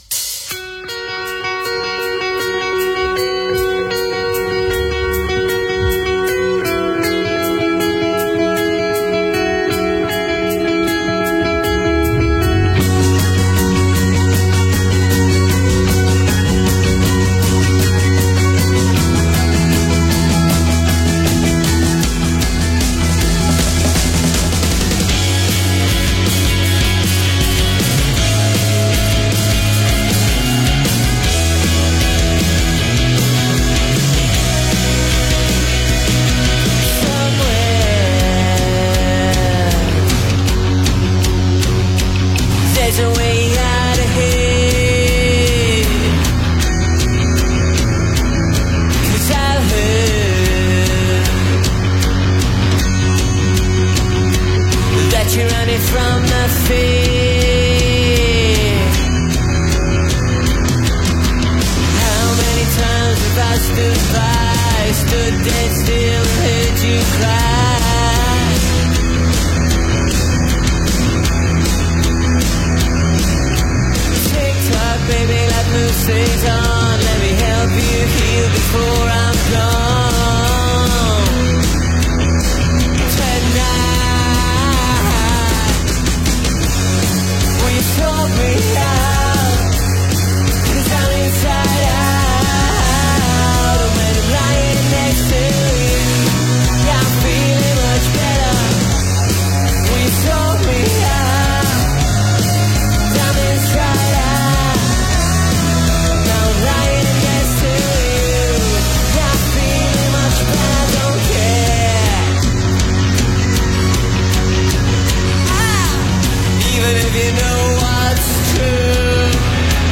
recorded live at the Cardiff Students Union
vocals, guitar, spoken word
bass, vocals